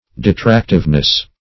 Detractiveness \De*tract"ive*ness\, n.